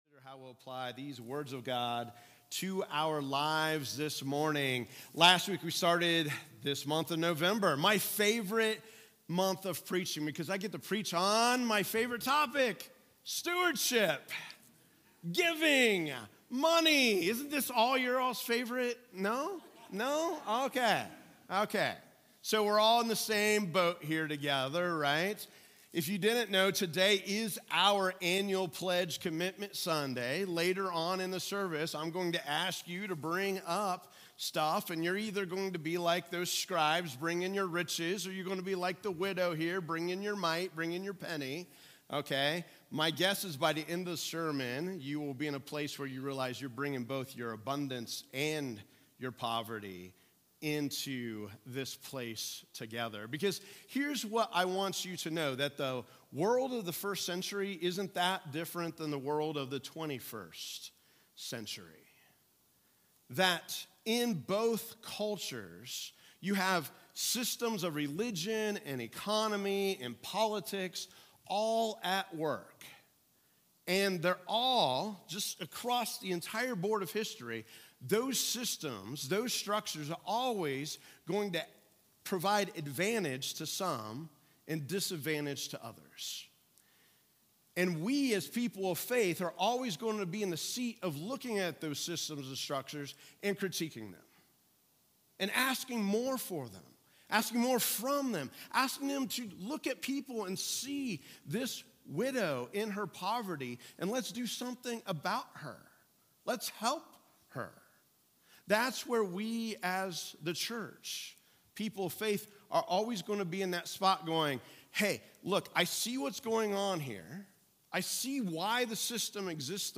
Sermons | Grace Presbyterian Church